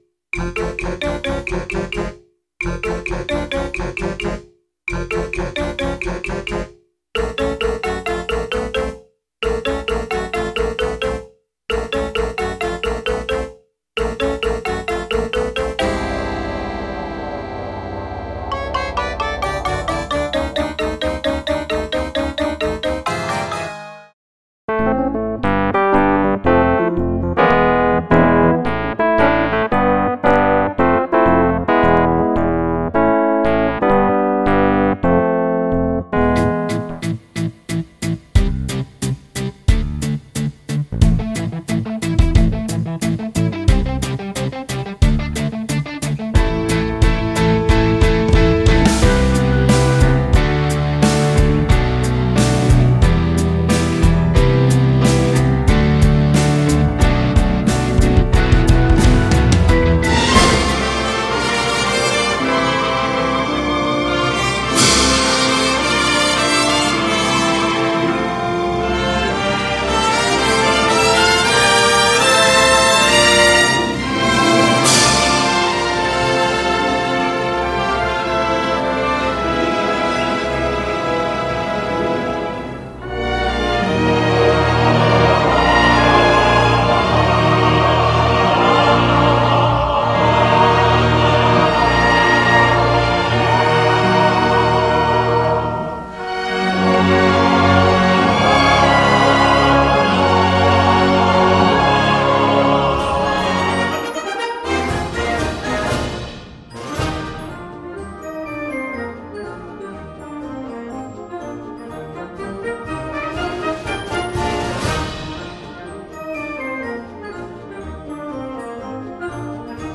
The Bluebell Theater Incident Overture